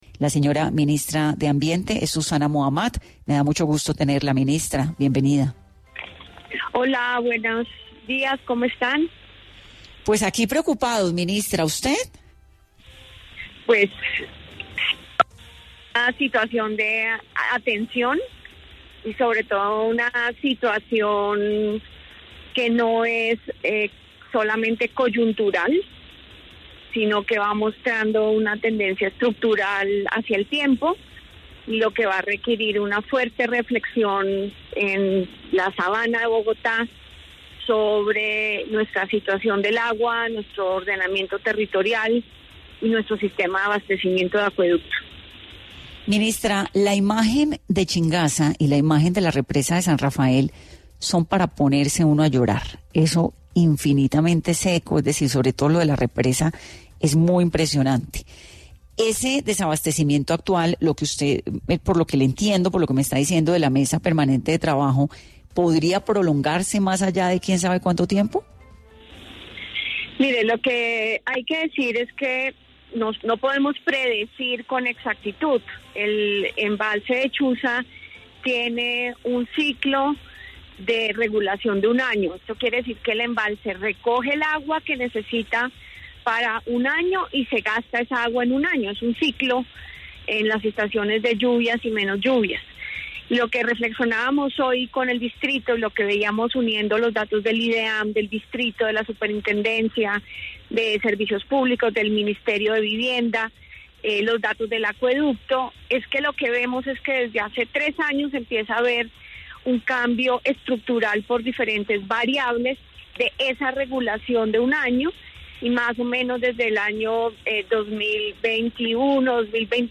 En Caracol Radio, la Ministra de Ambiente, Susana Muhamad, se pronunció sobre la crisis de escasez de agua que enfrenta Bogotá y los municipios circundantes. En una mesa de trabajo conjunta con autoridades locales, se discutieron medidas para enfrentar la situación actual y abordar la tendencia estructural hacia una escasez cada vez mayor.